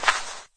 default_sand_footstep.2.ogg